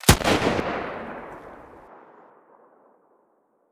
Sniper1_Shoot 04.wav